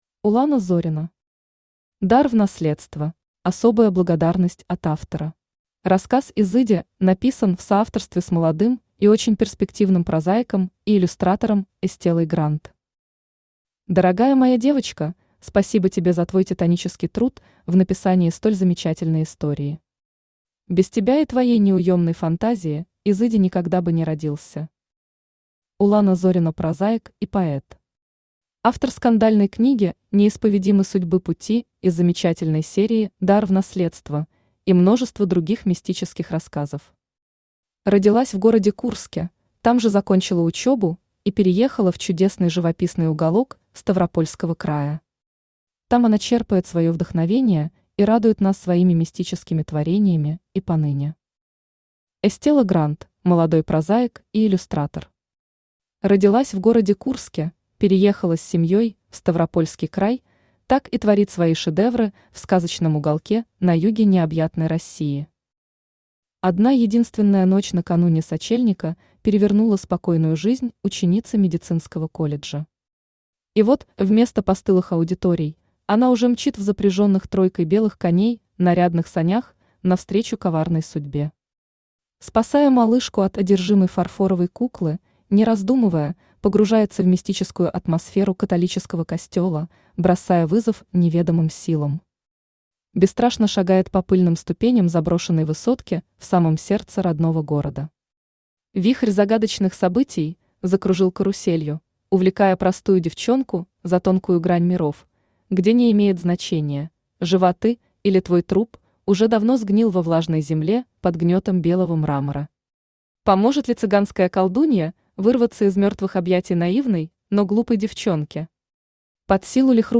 Аудиокнига Дар в наследство | Библиотека аудиокниг
Aудиокнига Дар в наследство Автор Улана Зорина Читает аудиокнигу Авточтец ЛитРес.